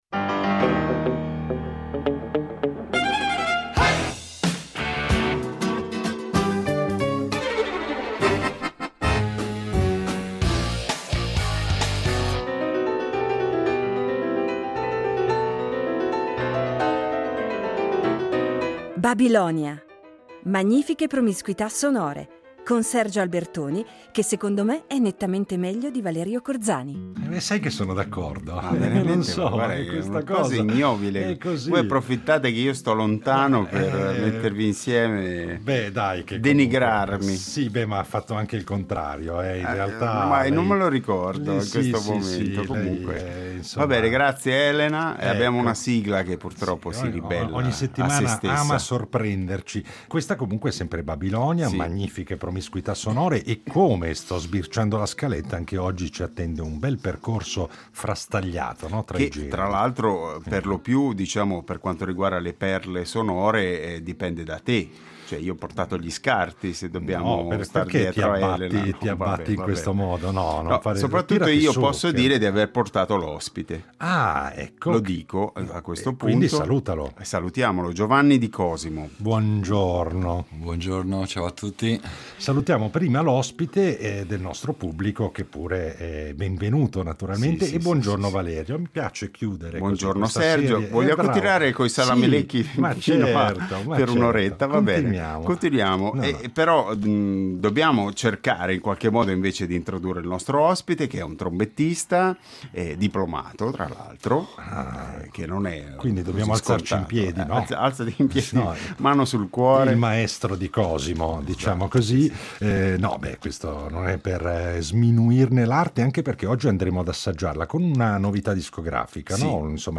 Noi in realtà lo abbiamo accolto sulla nostra arca radiofonica una decina d’anni fa, ma ci sembra doveroso ricordarlo a pochi mesi dalla sua prematura scomparsa, avvenuta il 17 giugno scorso, e quindi riproponiamo volentieri quella puntata.